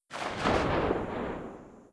Shotgun1_ShootTail 01.wav